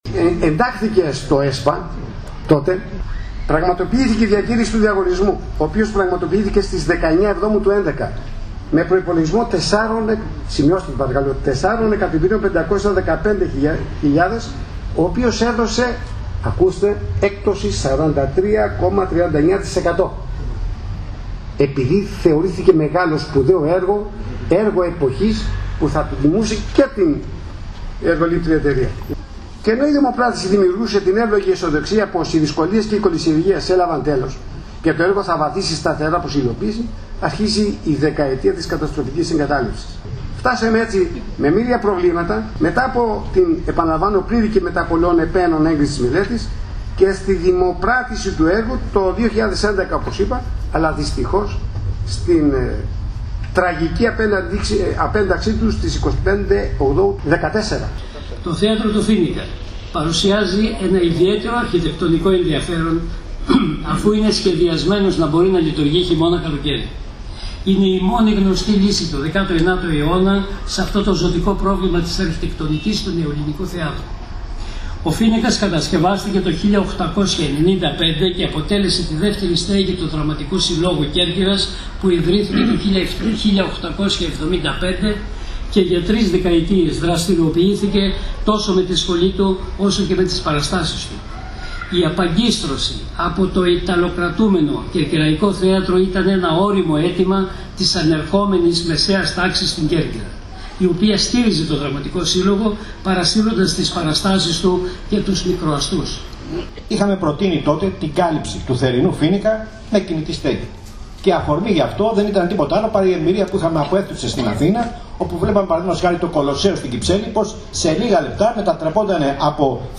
Την αναγκαιότητα της άμεσης και ουσιαστικής διάσωσης και ανάπλασης του ιστορικού θεάτρου Φοίνικας στην πόλη της Κέρκυρας, τόνισαν οι εκπρόσωποι των φορέων του νησιού σε εκδήλωση που διοργανώθηκε με τη συμμετοχή πλήθος κόσμου.